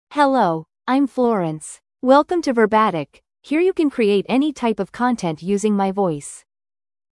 Florence — Female English (United States) AI Voice | TTS, Voice Cloning & Video | Verbatik AI
FemaleEnglish (United States)
Florence is a female AI voice for English (United States).
Voice sample